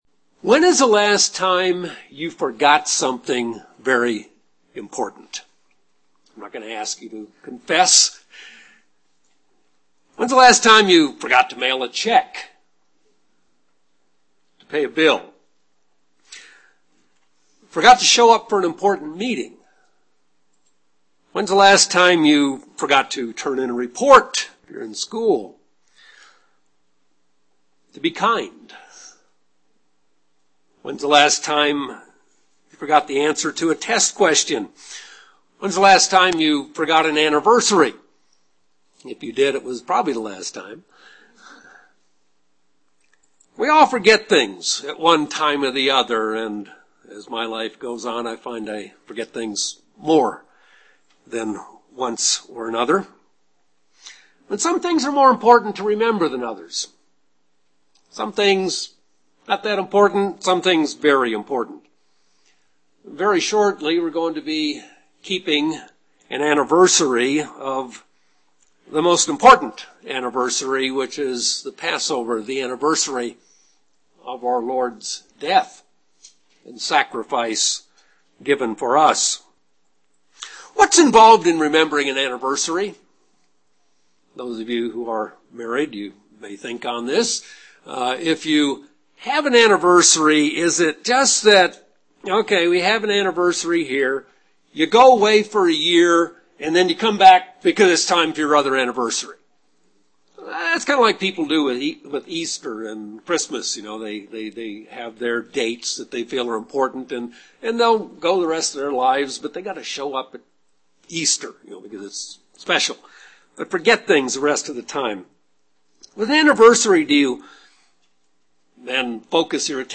God reinforces the importance of the Passover and Days of Unleavened Bread by telling us to remember them and their significance to God and to us. This sermon is a review of the important things that God wants us to remember.
Given in Albuquerque, NM